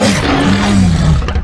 dragonRoar.wav